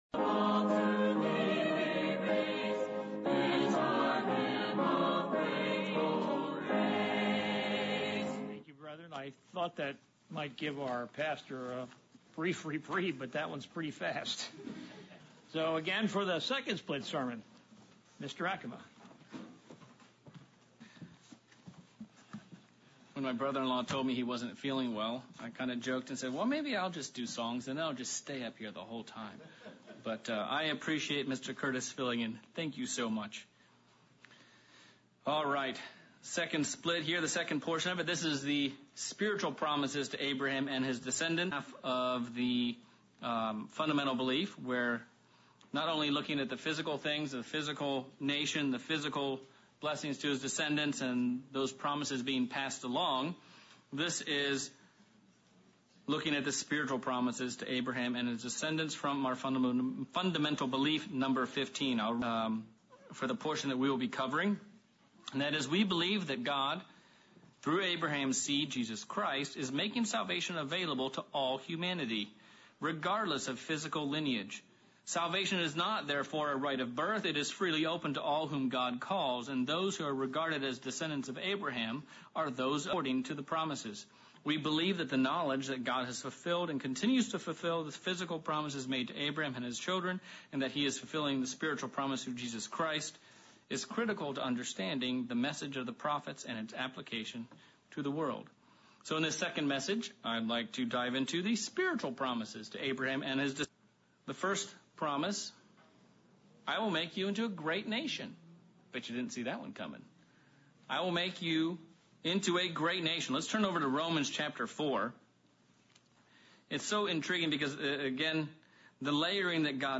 Sermon look at our Fundamentals of Belief #15 - The Spiritual promises to Abraham